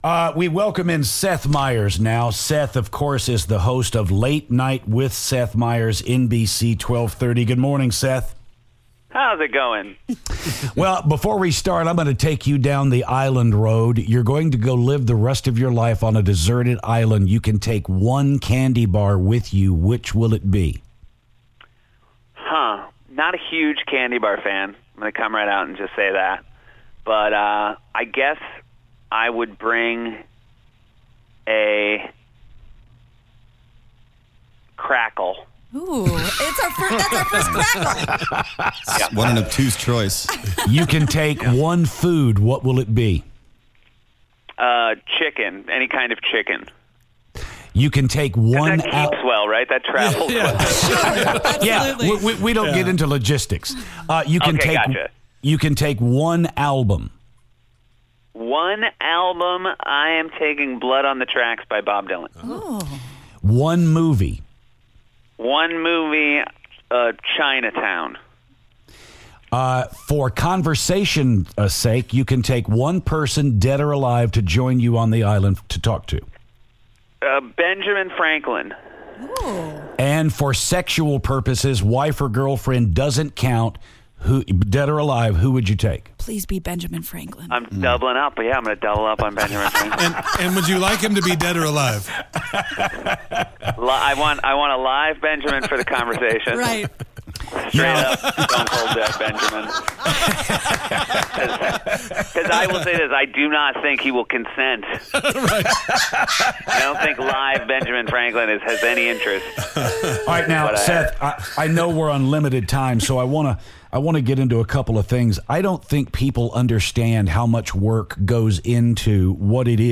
Late Night host Seth Meyers calls the show.